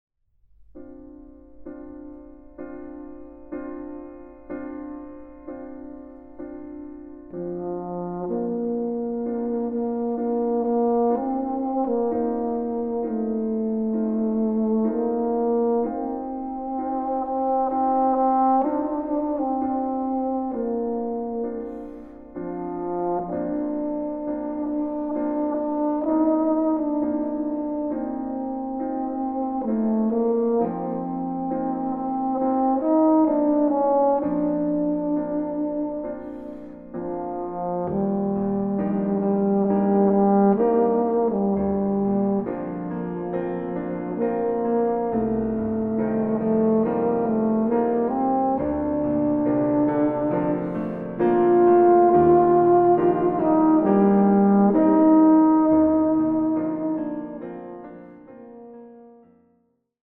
Recording: Mendelssohn-Saal, Gewandhaus Leipzig, 2025
Version for Euphonium and Piano